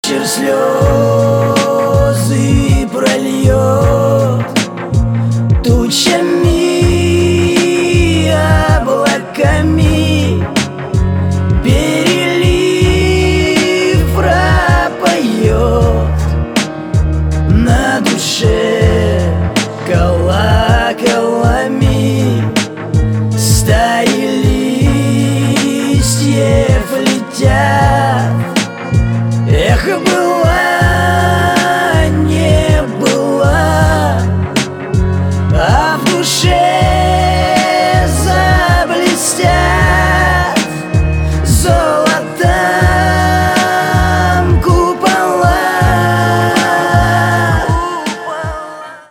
• Качество: 320, Stereo
душевные
русский рэп
русский шансон